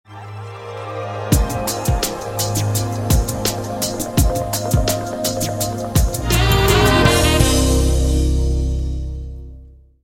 Ambient sound effects